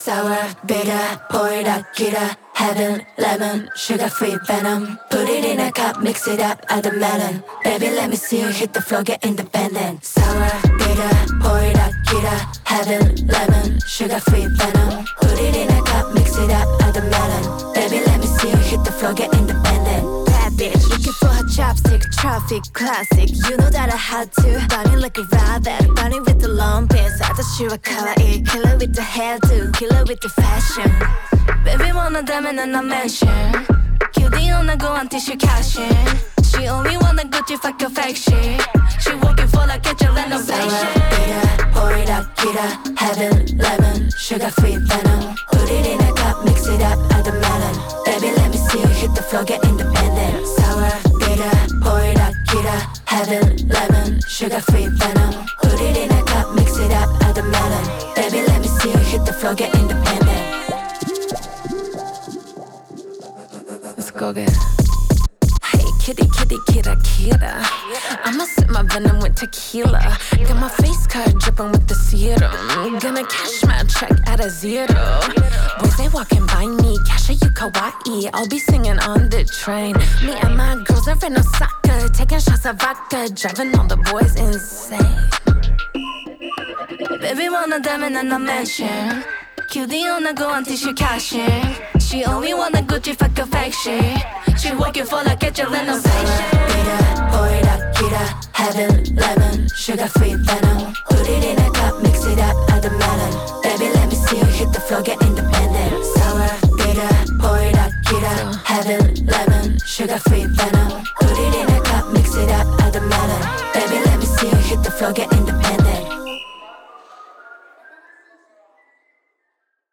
BPM95
Audio QualityPerfect (High Quality)
Genre: SEQUENCE 01.7 (J-Pop)